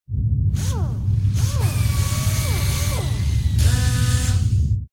repair2.ogg